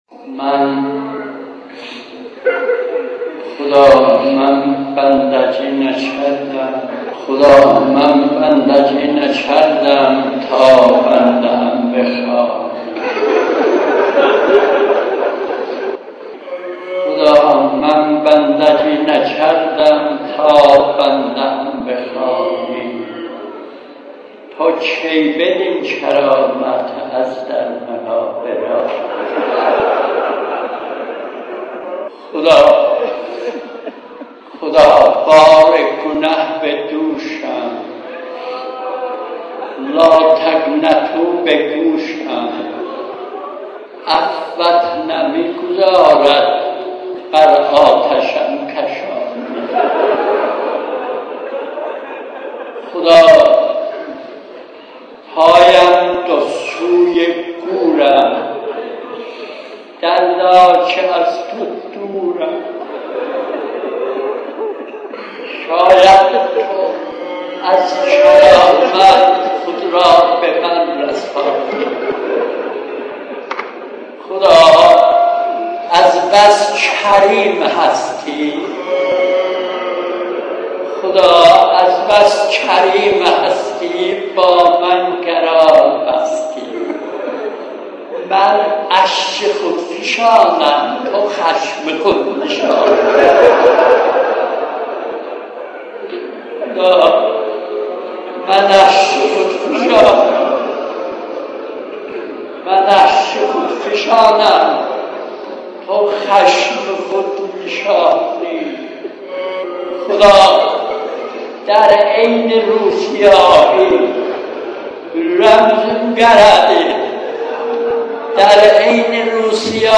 مناجات.mp3